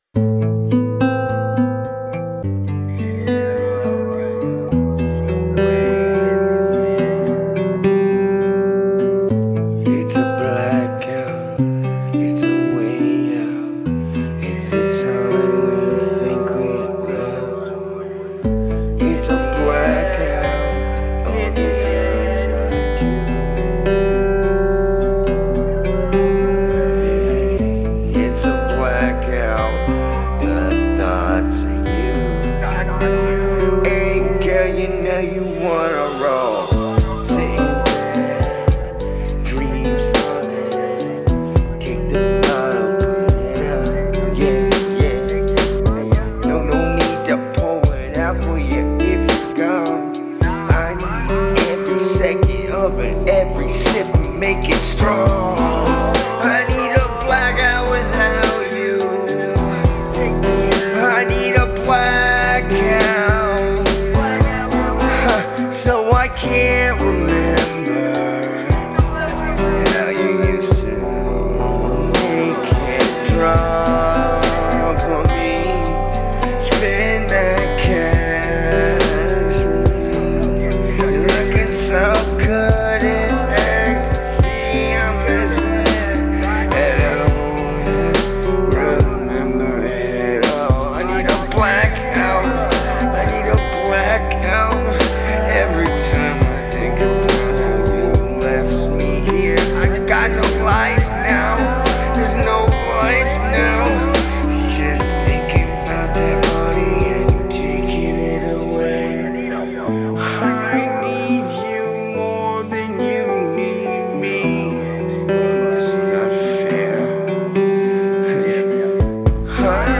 Date: 2026-09-06 · Mood: dark · Tempo: 71 BPM · Key: C major